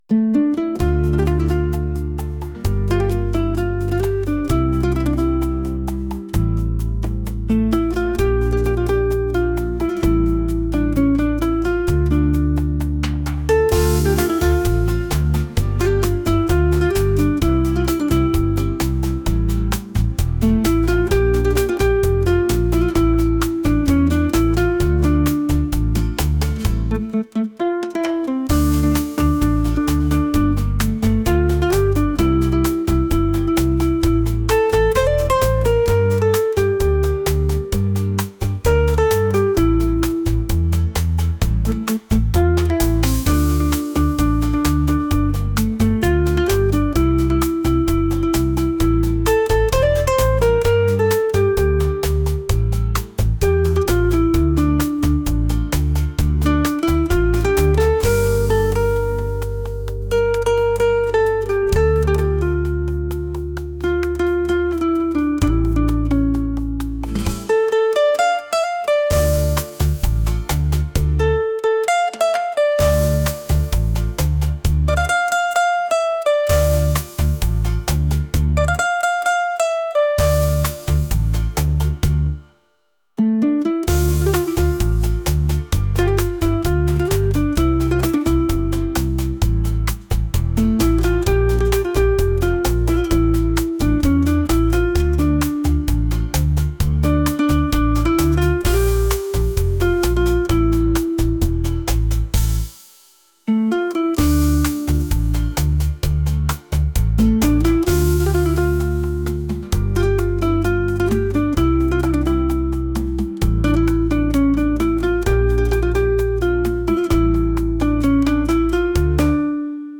world | traditional | rhythmic